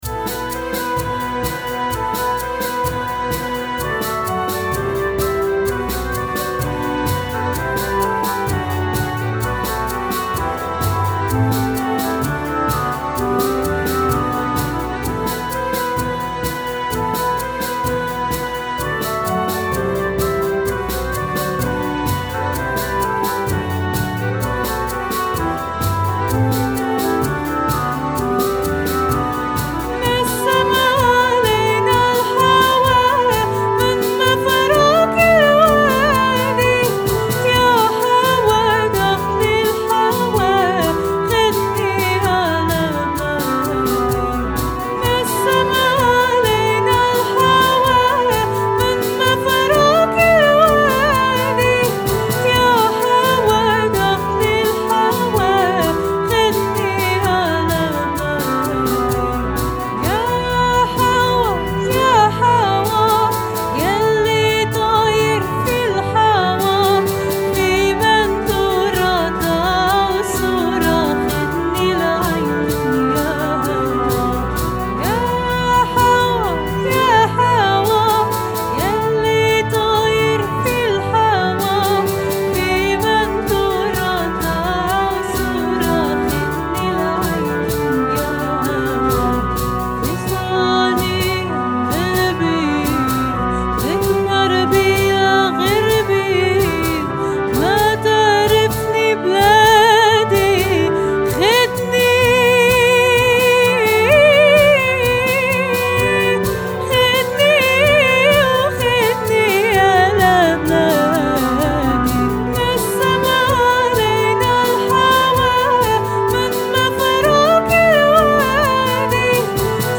LUSTRUM-3.-Nassam-Alayna-El-Hawa (met zang).mp3